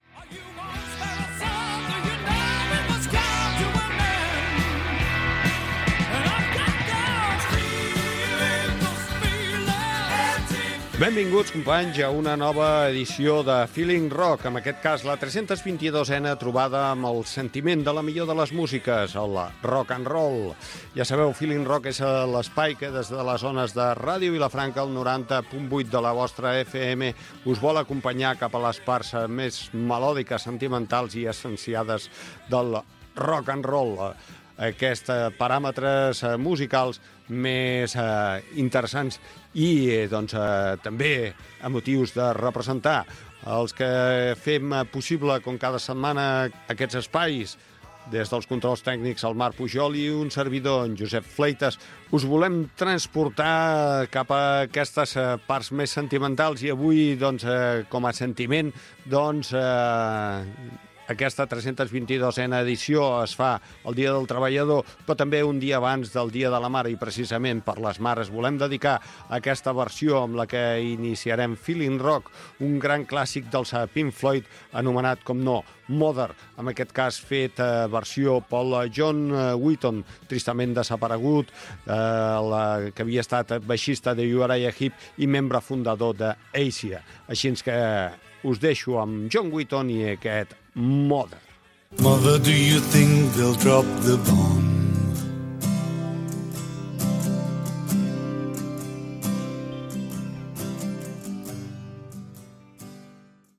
Presentació de l'edició 322 amb identificació de la ràdio i tema musical
Musical
FM